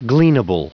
Prononciation du mot gleanable en anglais (fichier audio)